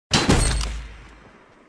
Fortnite Headshot